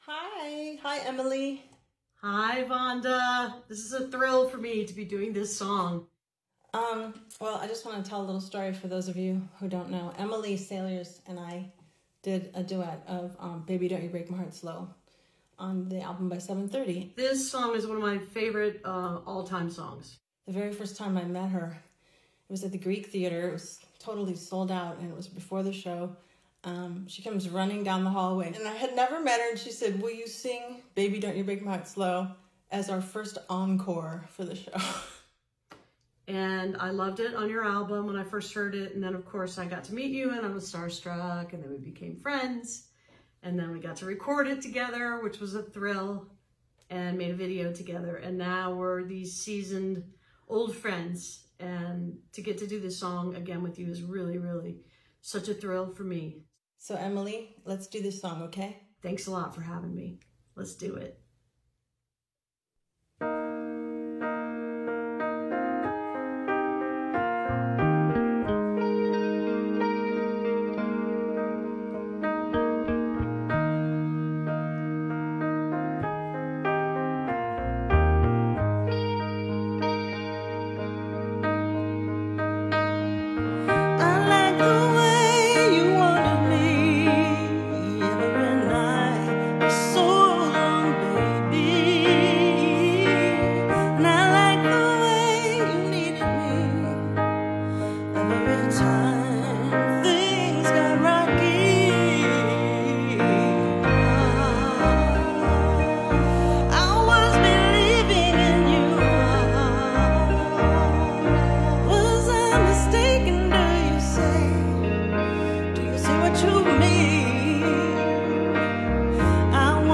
(studio remake)
(captured from webcast)